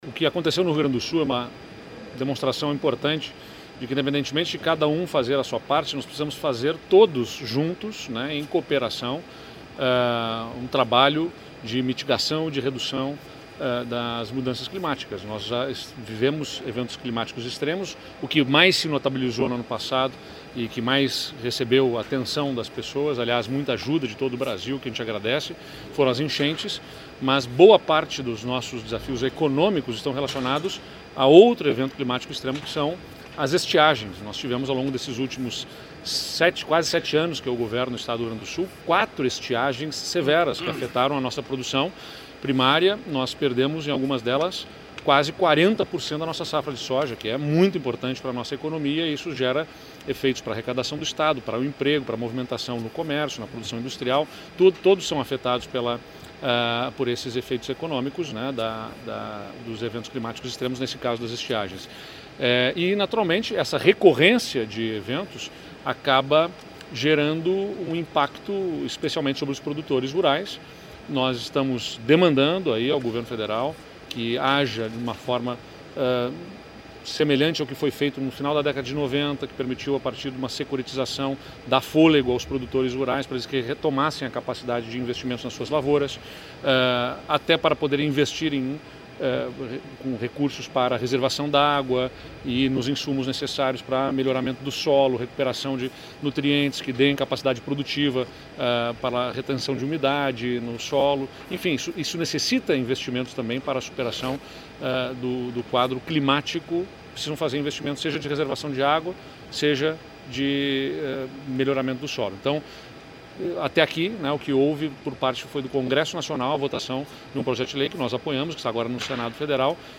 Sonora do governador do Rio Grande do Sul, Eduardo Leite, sobre a 13ª edição do Cosud